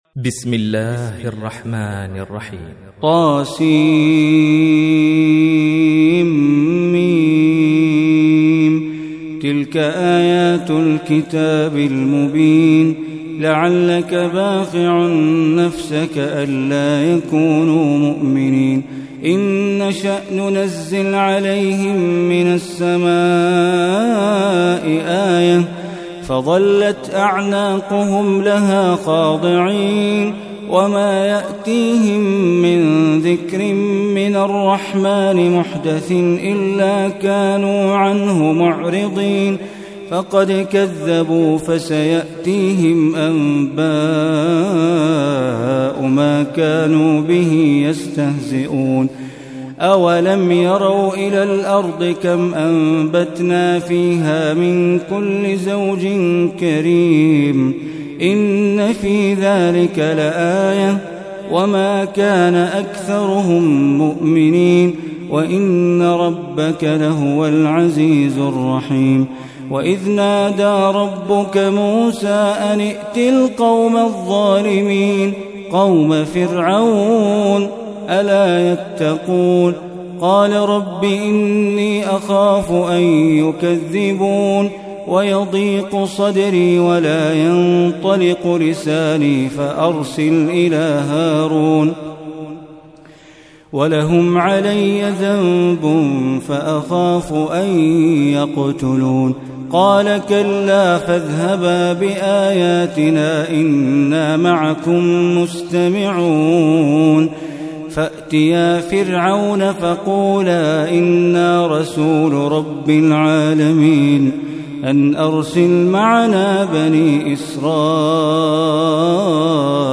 Surah Ash Shuraa Recitation by Bandar Baleela
Surah Ash Shura, is 26th chapter of Holy Quran. Listen or play online mp3 recitation in the voice of Sheikh Bandar Baleela.